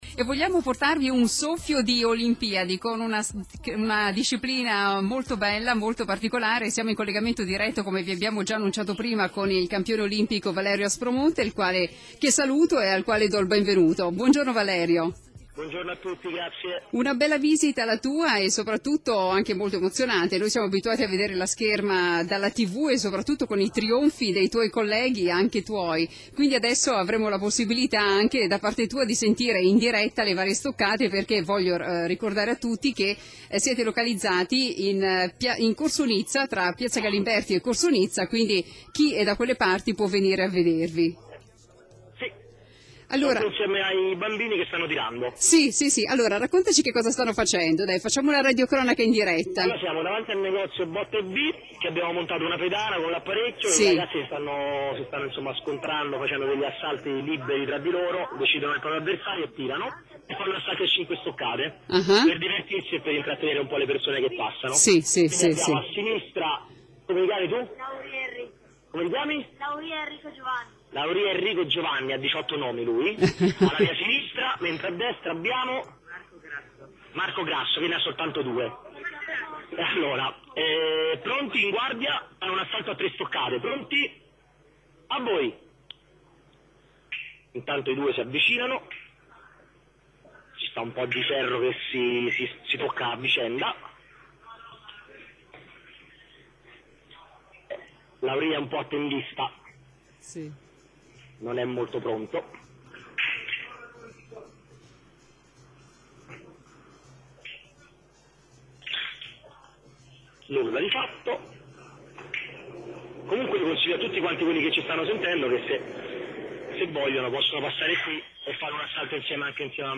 Intervista radiofonica a Valerio Aspromonte
intervista_aspromonte.mp3